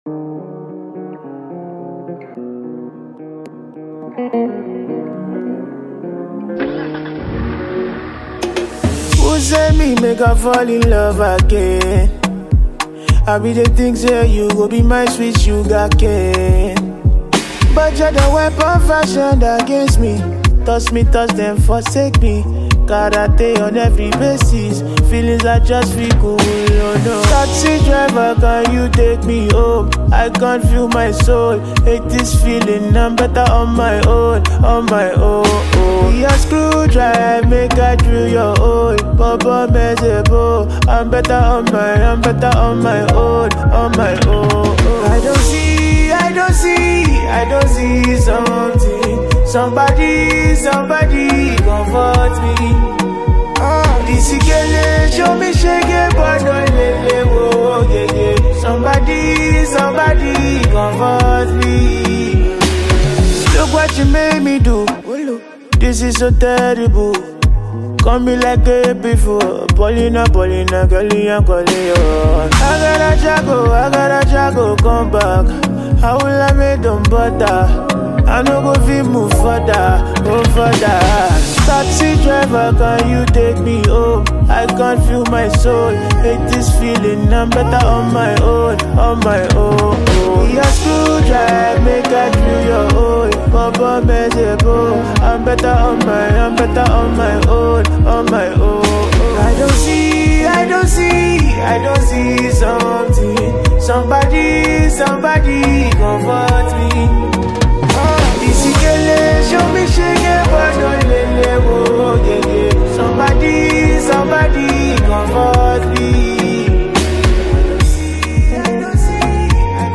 Get this energizing song